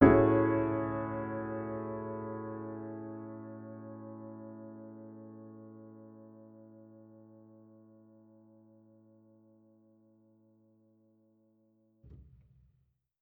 Index of /musicradar/jazz-keys-samples/Chord Hits/Acoustic Piano 2
JK_AcPiano2_Chord-Amaj13.wav